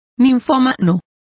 Also find out how ninfomano is pronounced correctly.